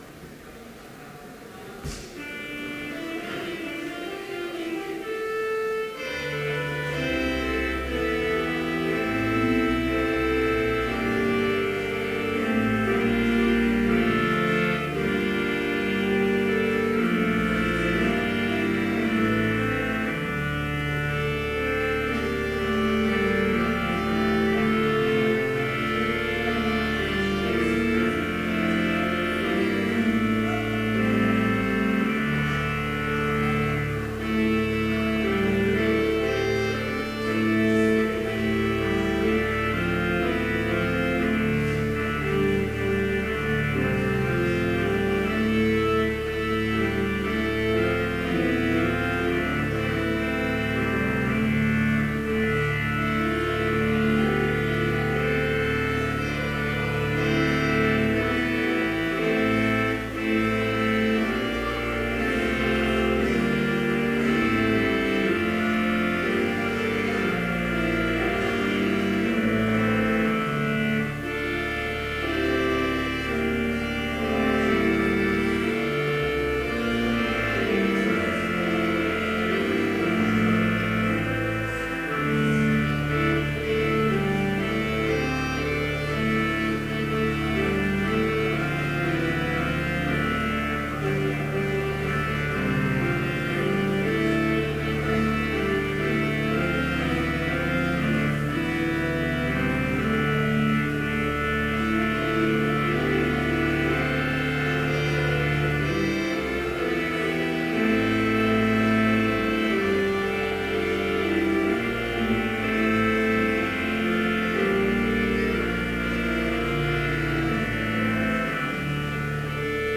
Complete service audio for Chapel - May 2, 2014